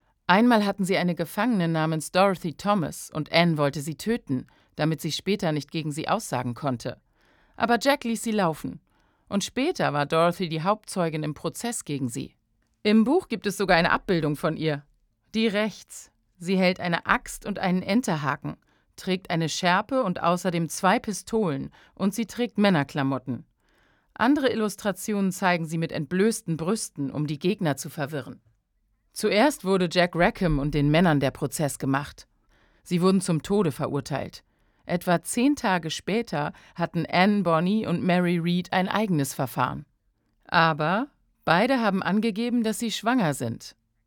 dunkel, sonor, souverän, markant, sehr variabel
Voice Over
Doku